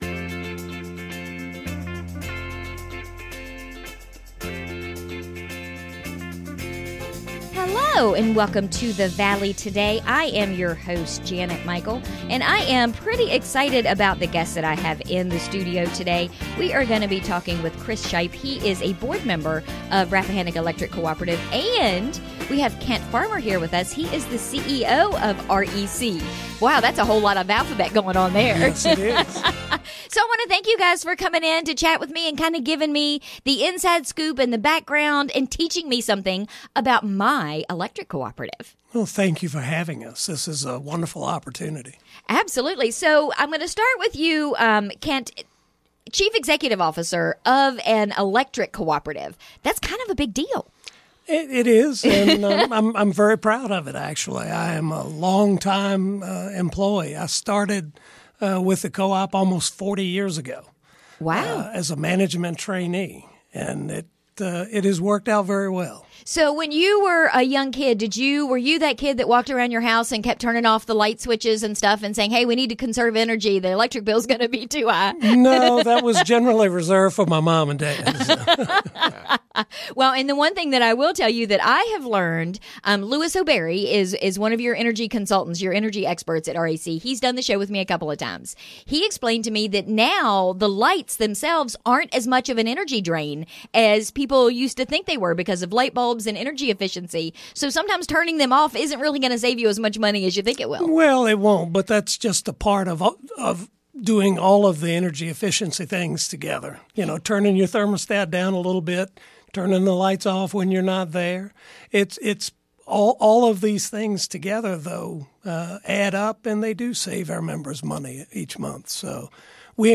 Our conversation continued after the show wrapped on-air; you can hear the entire conversation here.